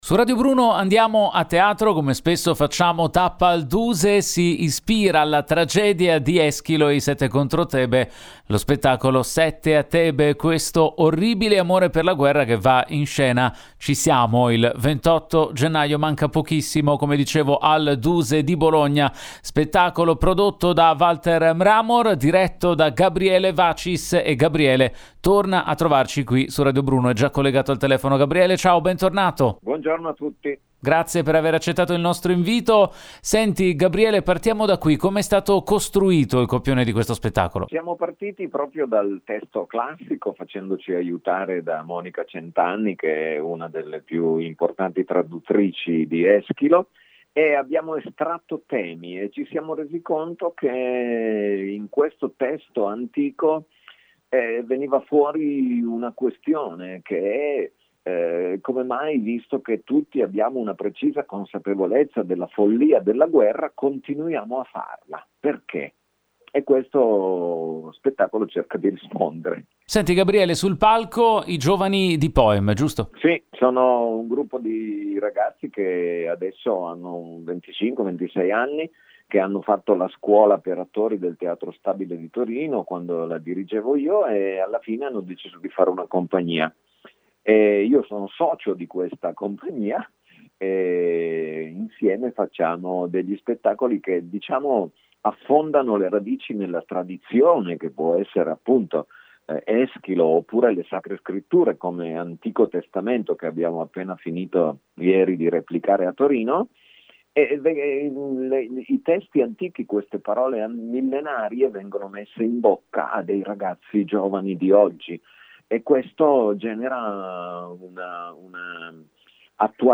Home Magazine Interviste “Sette a Tebe, Questo orribile amore per la guerra” in scena al...